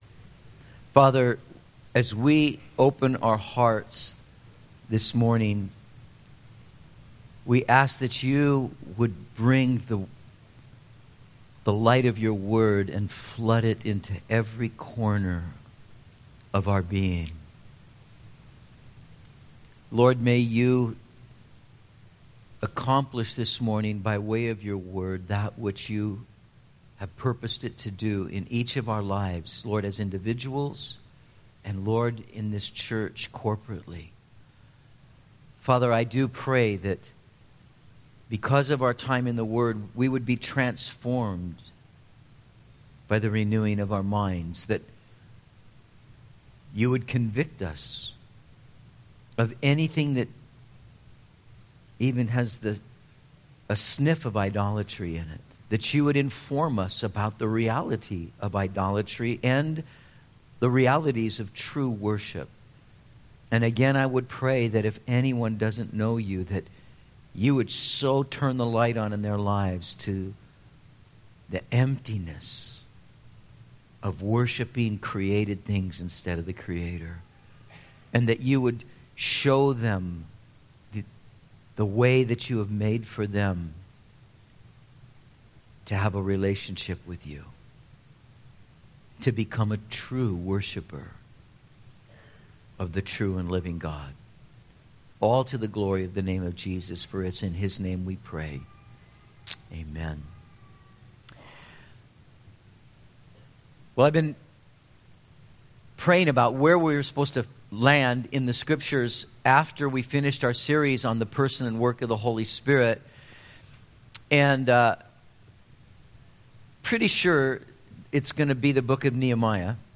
05/04/14 Idolatry, Worship and Mission - Metro Calvary Sermons